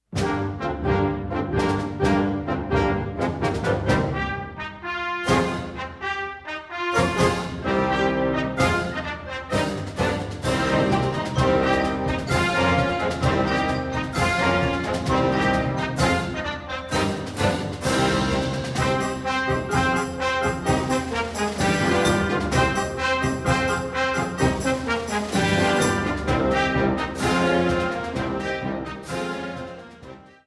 Kategorie Blasorchester/HaFaBra
Unterkategorie Internationale Folklore
Besetzung Ha (Blasorchester)